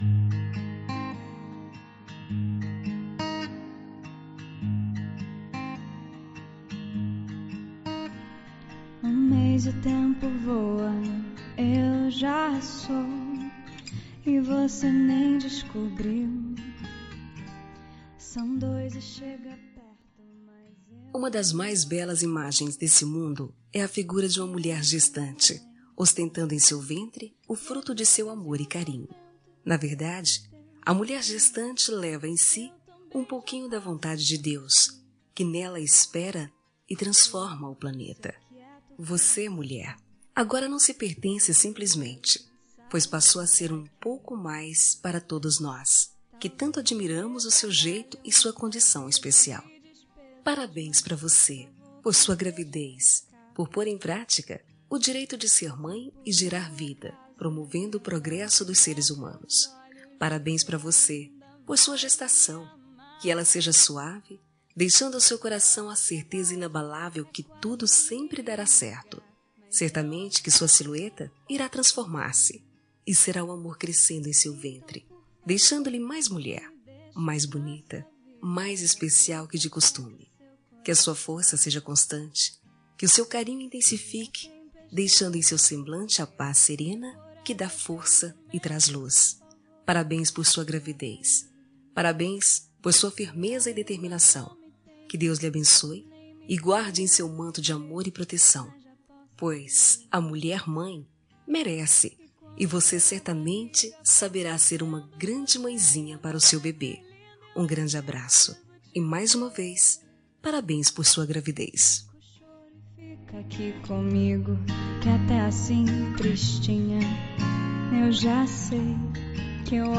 Dia das Mães – Para Mãe Grávida – Voz Feminina – Cód: 6700